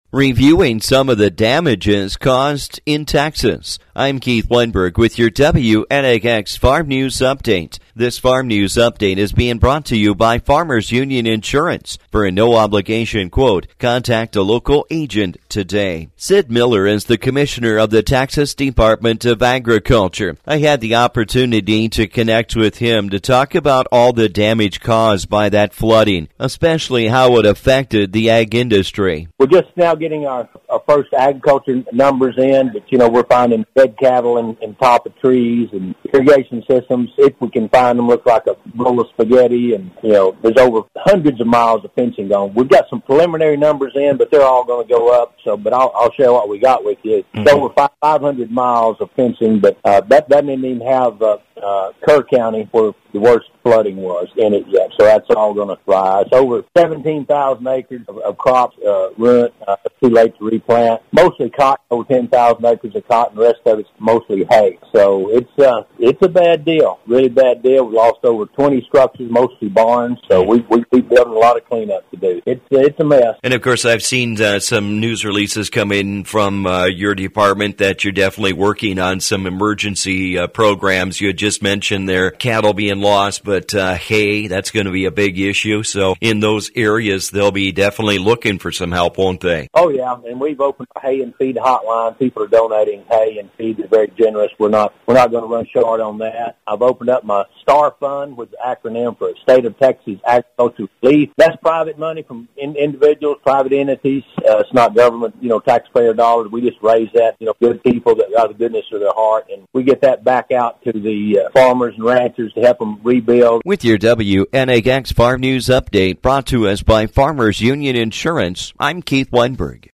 We’ve been following the Flooding in Texas and how that has played a role within the Ag Industry. I get an update from Sid Miller, Commissioner of the Texas Department of Agriculture.